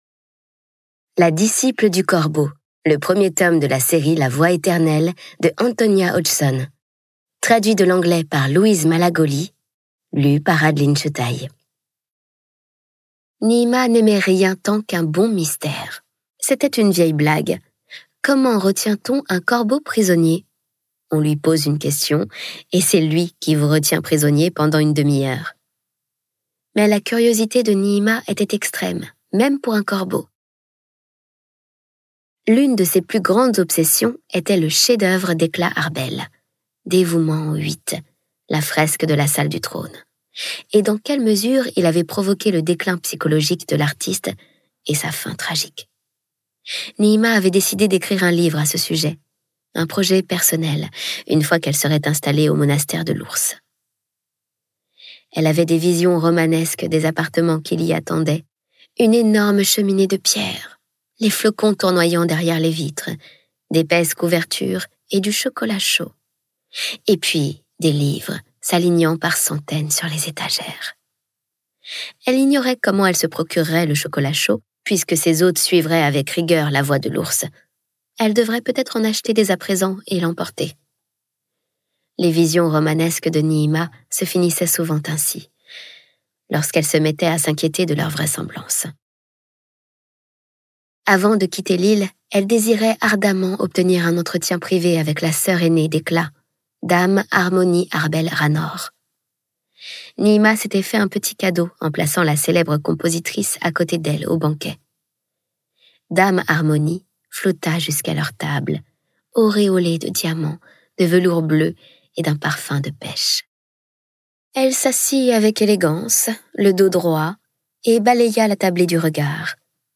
Diffusion distribution ebook et livre audio - Catalogue livres numériques
Interprétation humaine Durée : 25H33 33 , 95 € précommande Ce livre est accessible aux handicaps Voir les informations d'accessibilité